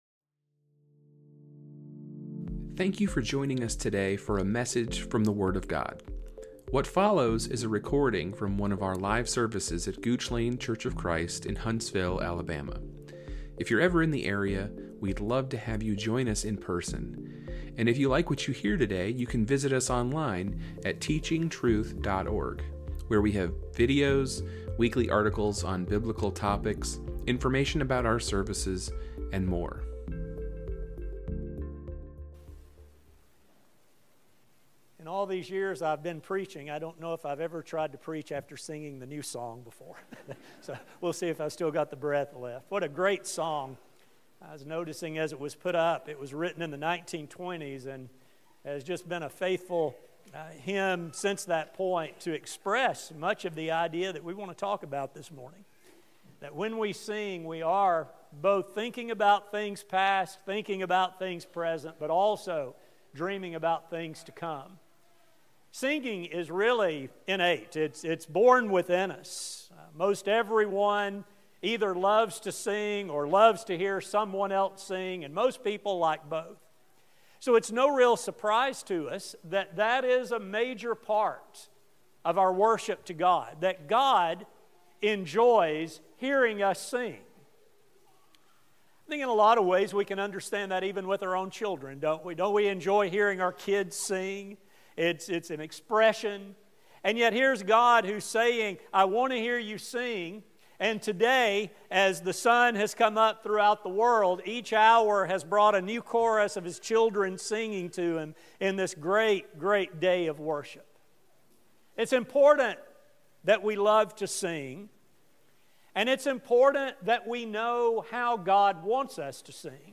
This study will discuss the history of singing as recorded in the Bible, with a focus on God’s expectations and instructions for modern saints. A sermon